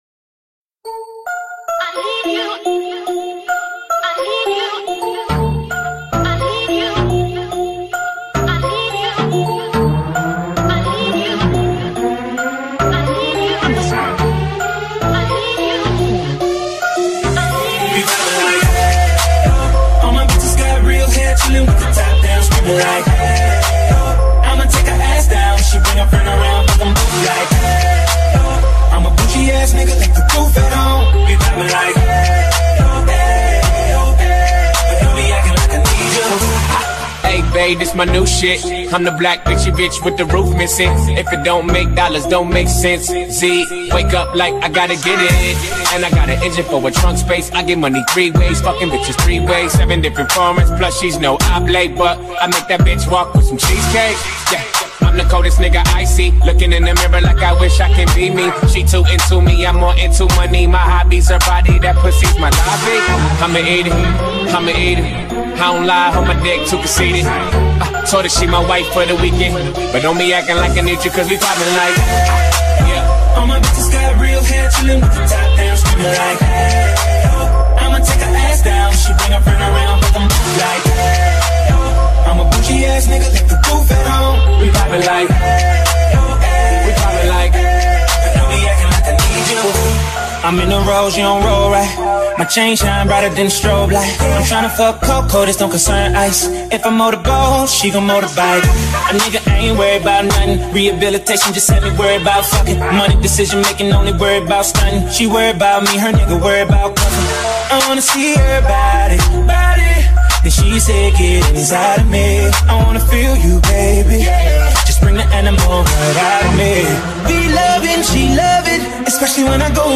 Ajout du fichier test en mono
test_mono.ogg